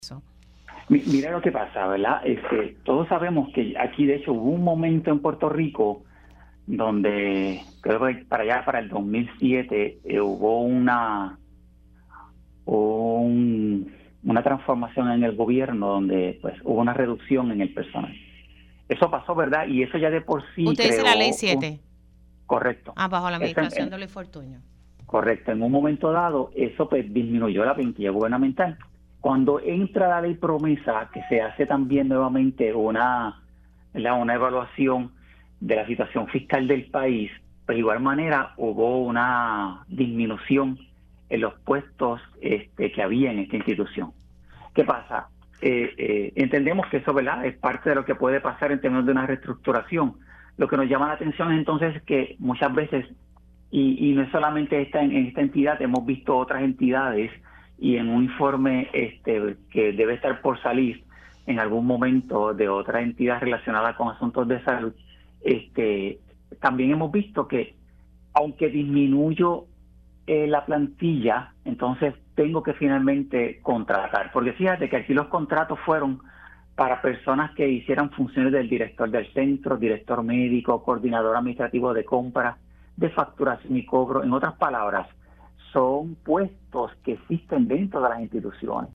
El subcontralor, David de Jesús, explicó en Pega’os en la Mañana que los contratistas facturaron por una cantidad mayor a la que se indicaba en su contrato, y que se emitió información incompleta a la hora de manejar los desembolsos.